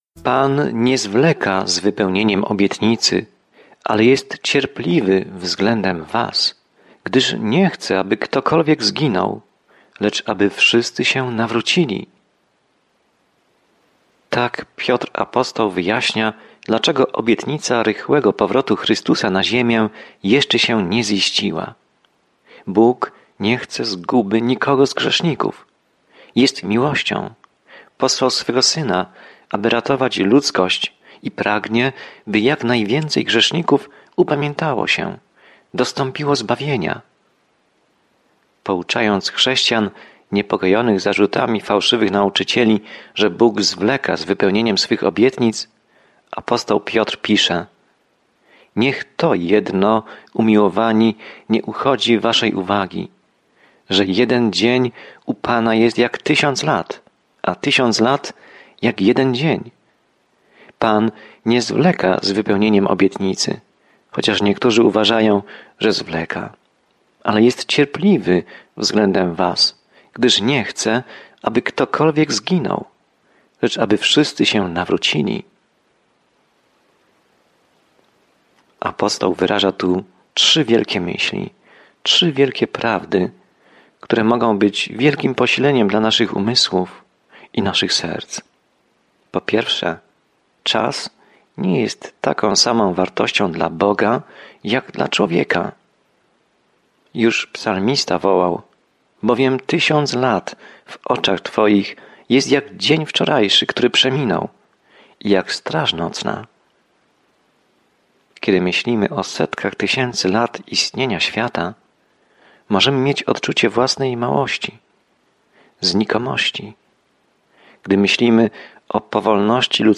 Pismo Święte 2 Piotra 3:8-10 Dzień 9 Rozpocznij ten plan Dzień 11 O tym planie Drugi list Piotra jest w całości poświęcony łasce Bożej – jak nas zbawiła, jak nas utrzymuje i jak możemy w niej żyć – pomimo tego, co mówią fałszywi nauczyciele. Codzienna podróż przez 2 List Piotra, słuchanie studium audio i czytanie wybranych wersetów słowa Bożego.